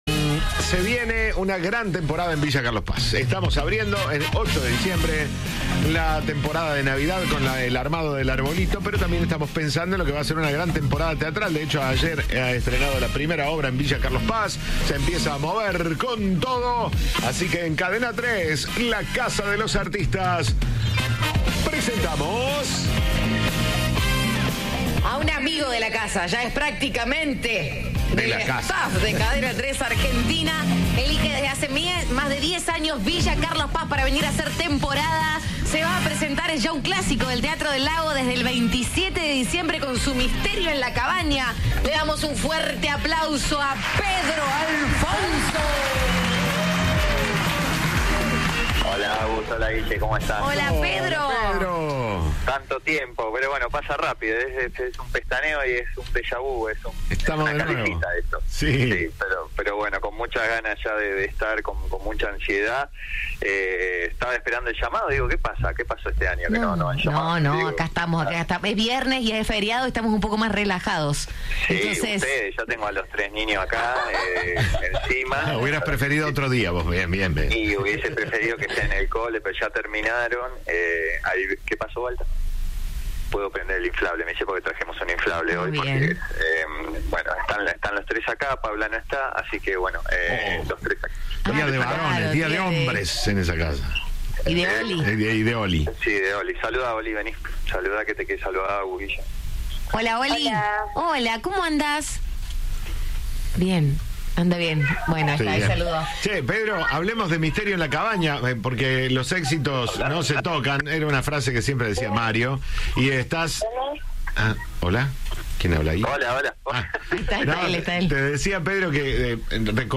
"El objetivo es no entrar en una rutina para pasarla mejor", dijo el actor en diálogo con Cadena 3.
Entrevista de "Siempre Juntos".